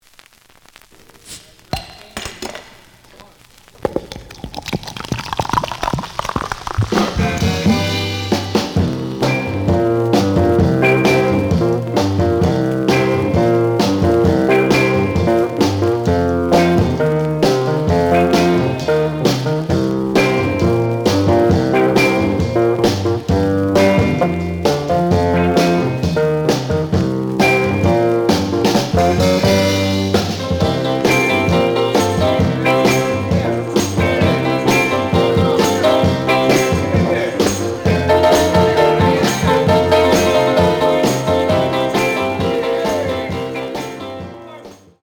The audio sample is recorded from the actual item.
●Format: 7 inch
●Genre: Funk, 60's Funk